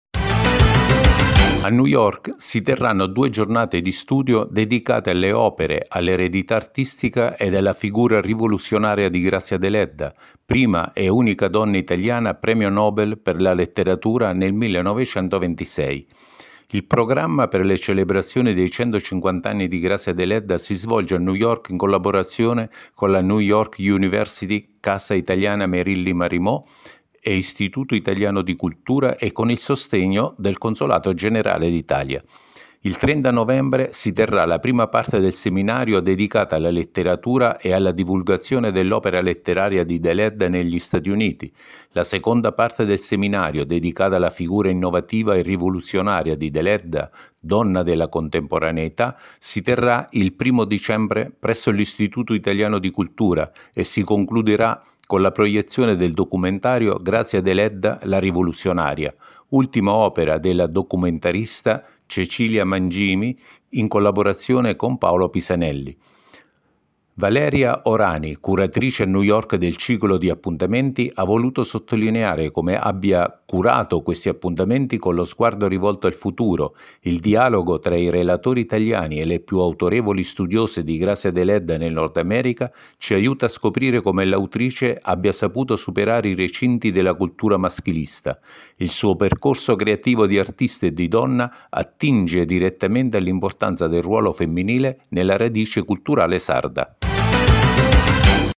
A New York giornate di studio su Grazia Deledda (audio notizia)